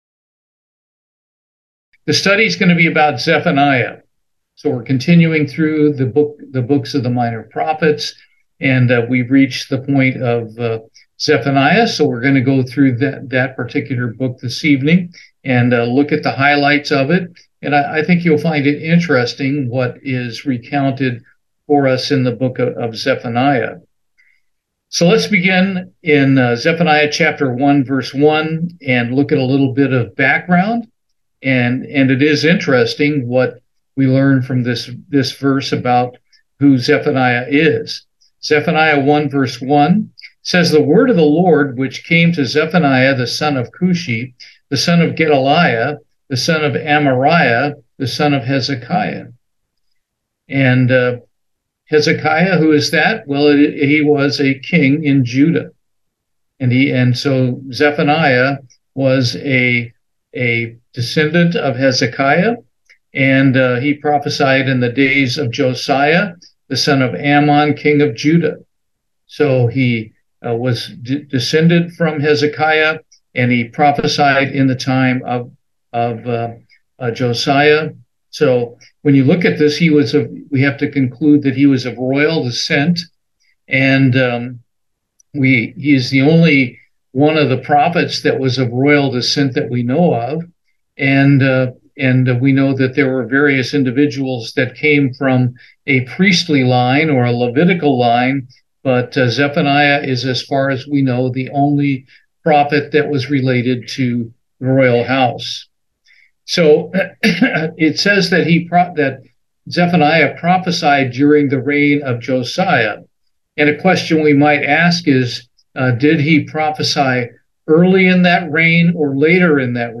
Bible Study, Zephaniah